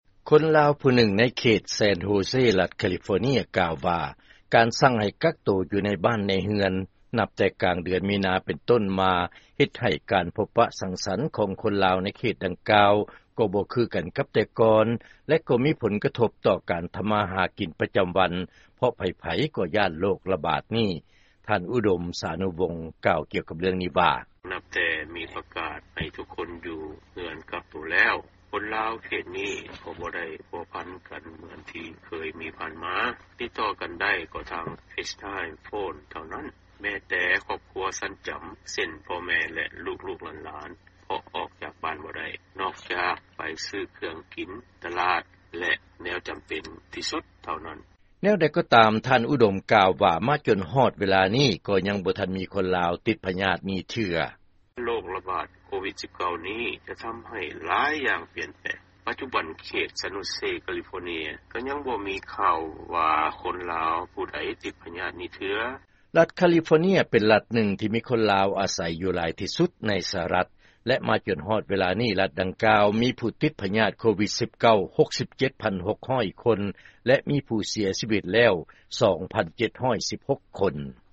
ເຊີນຟັງຂ່າວ ໂຄວິດ-19 ເຮັດໃຫ້ຊາວລາວ ໃນເຂດແຊນໂຮເຊ ໄປມາຫາສູ່ກັນລຳບາກ